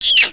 pain1.wav